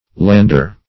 Lander \Land"er\, n.